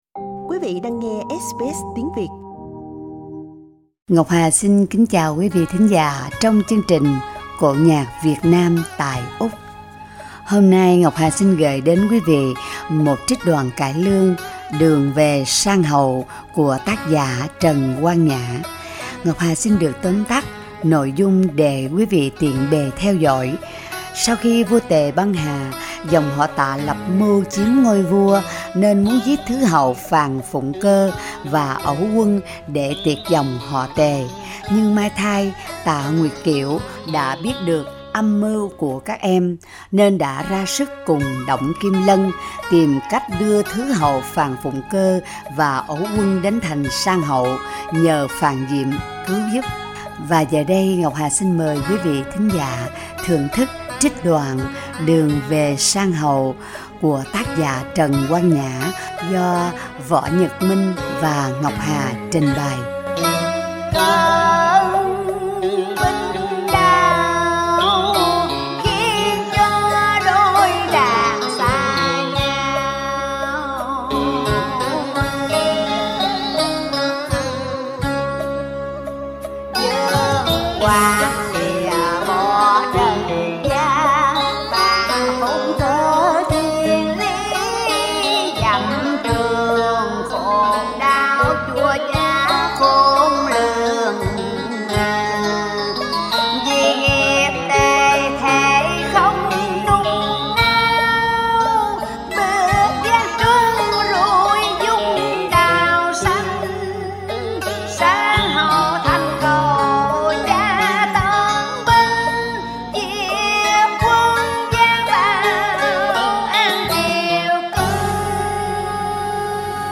Giọng ca Vọng cổ